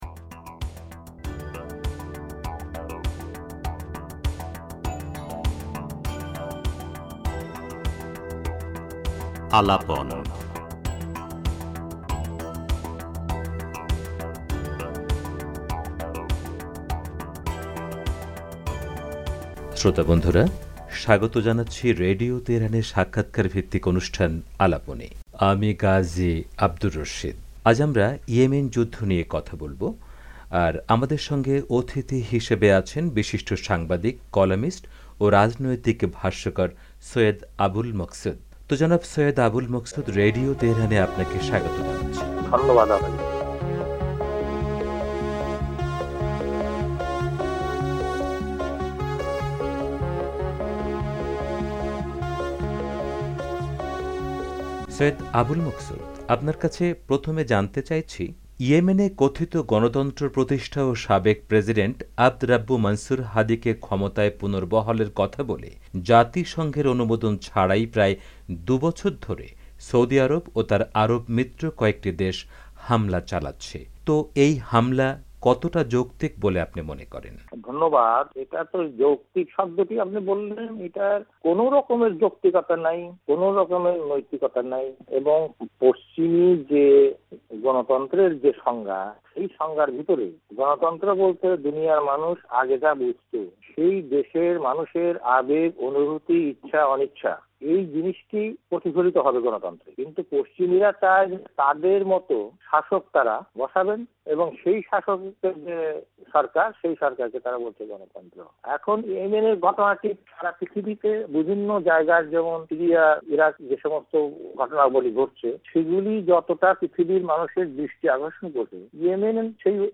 ইয়েমেনে গত দু বছর ধরে সৌদি আরব ও তার আরব মিত্র কয়েকটি দেশ যে সামরিক হামলা চালাচ্ছে সে সম্পর্কে বাংলাদেশের বিশিষ্ট সাংবাদিক, কলামিস্ট ও রাজনৈতিক ভাষ্যকার সৈয়দ আবুল মকসুদ রেডিও তেহরানের সাথে এক্সক্লুসিভ সাক্ষাৎকার দিয়েছেন।